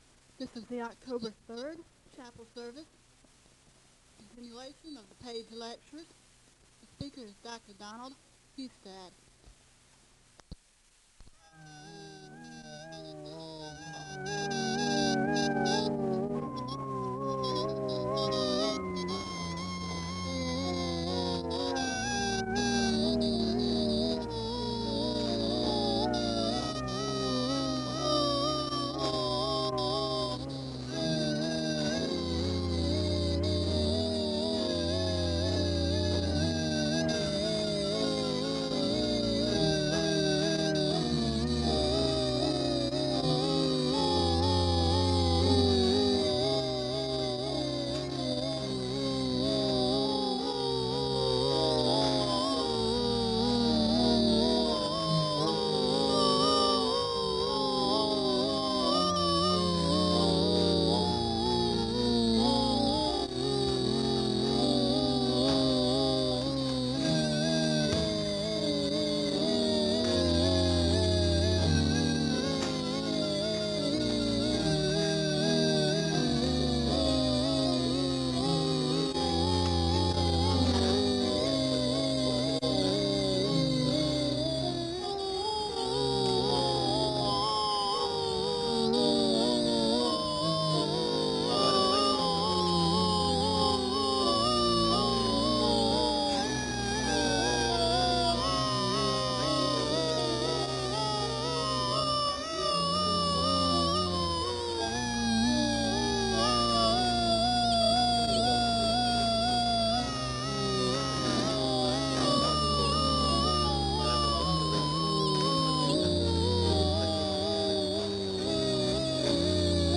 The service begins with organ music (00:00-06:34). The speaker gives a word of prayer (06:35-07:50).
The choir sings the anthem (10:21-12:08).